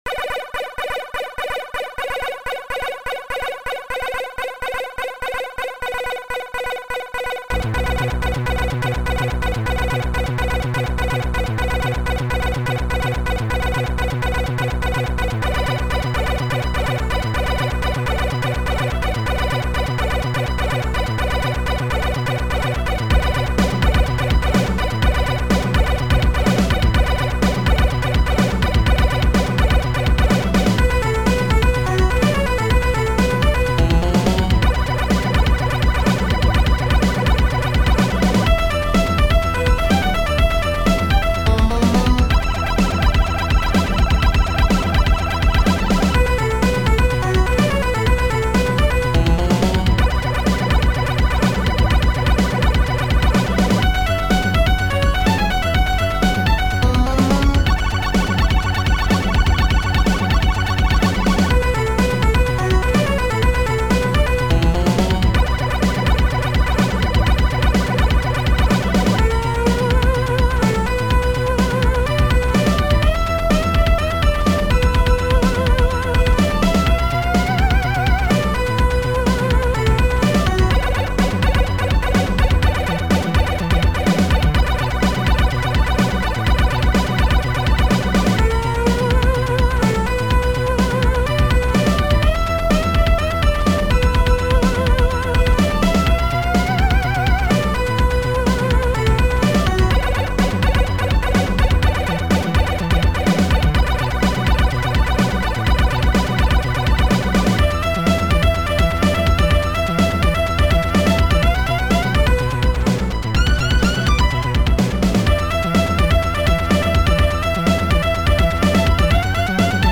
Synth